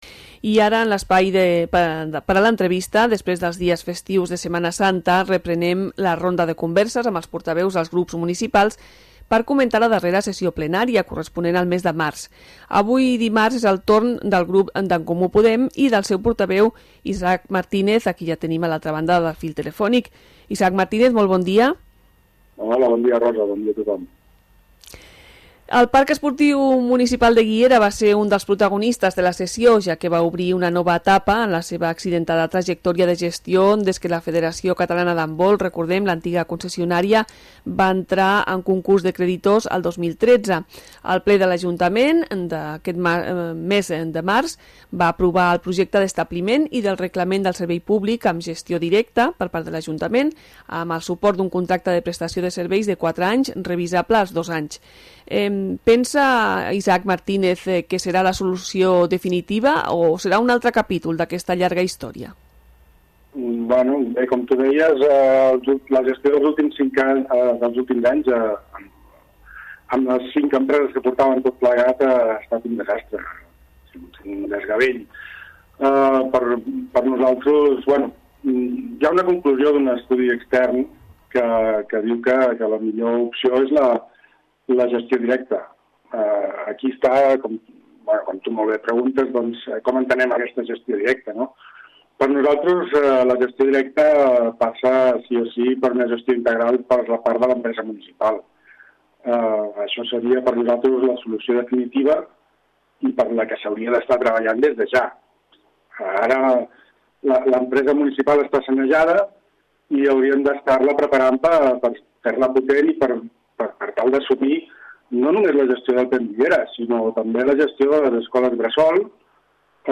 Entrevista-Isaac-Martínez-ECP-Ple-març.mp3